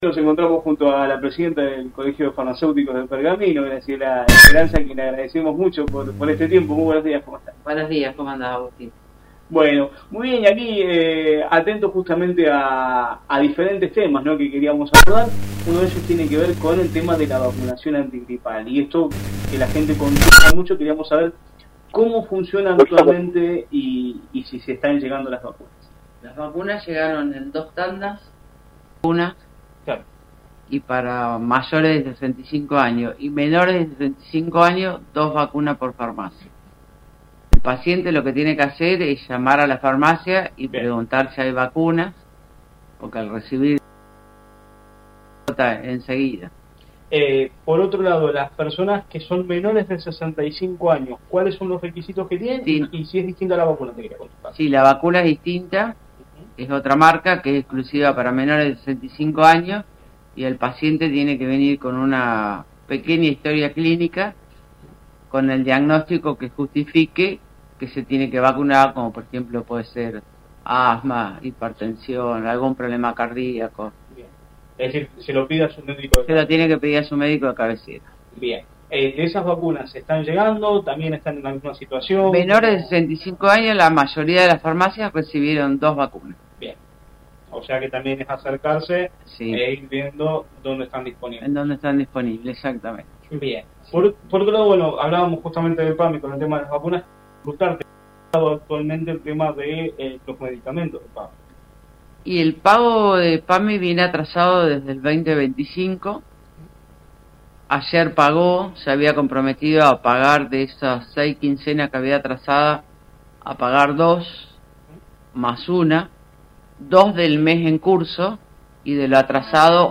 En el marco del programa La Mañana de la Radio de LT35 Radio Mon AM 1540, el móvil dialogó con